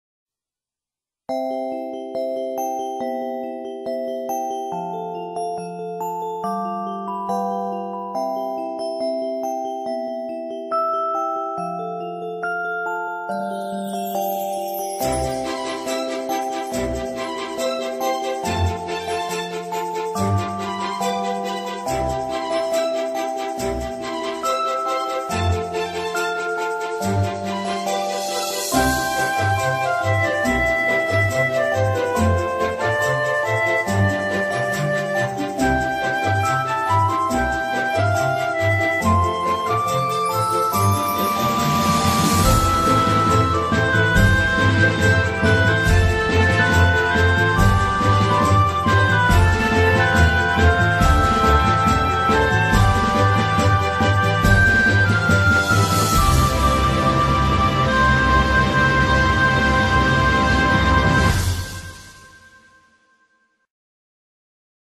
Magic Christmas - Sound Effects.mp3